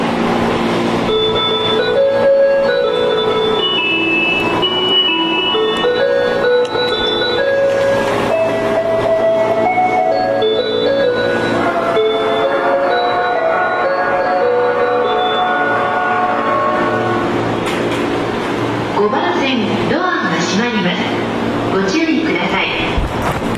ただ高架下なのでうるさく、一部音質が悪いホームがあり、玉に瑕です。